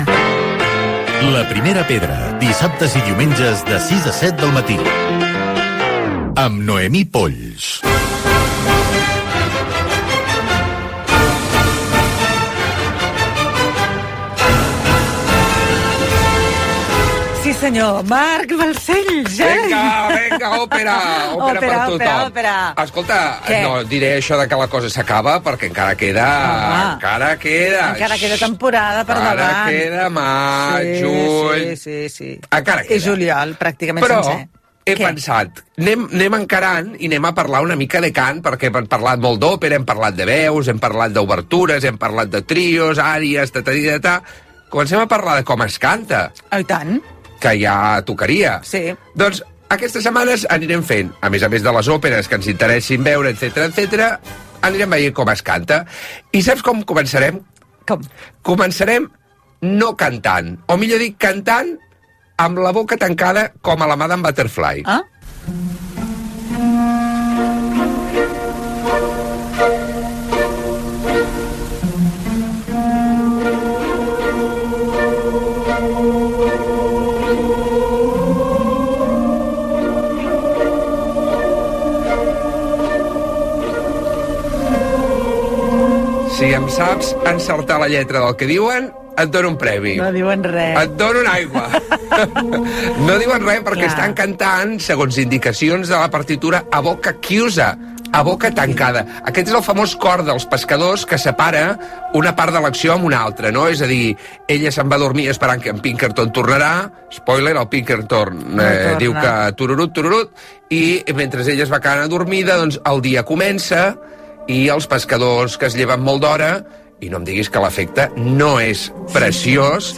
Indicatiu del programa, espai dedicat a l'òpera, record de l'any 1947 (dia de la verge de Montserrat) amb esment a l'Arxiu Sonor de la Ràdio a Catalunya de la Universitat Autònoma de Barcelona i a la Menció d'Honor que ha rebut als Premis Ràdio Associació de Catalunya Gènere radiofònic Entreteniment